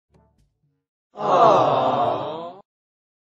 Aww Sound Effects